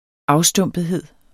Udtale [ -ˌsdɔmˀbəðˌheðˀ ]